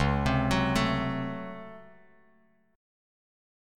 C#M7sus4#5 chord